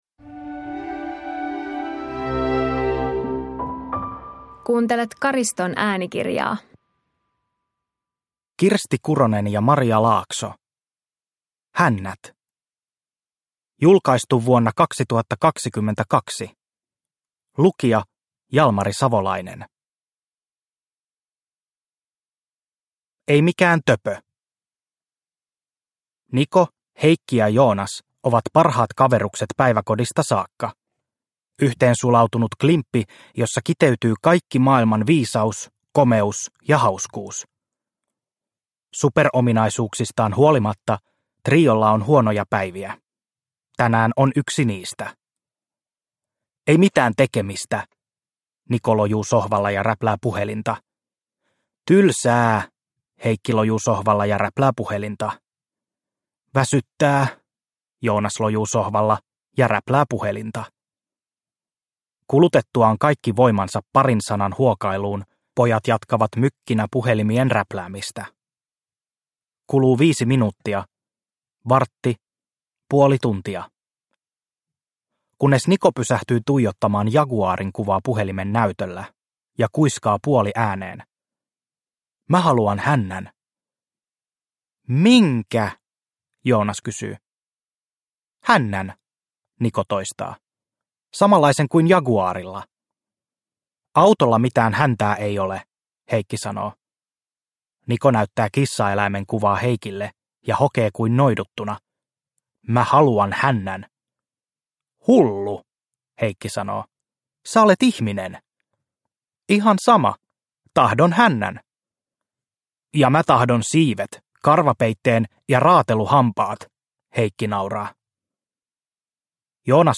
Hännät – Ljudbok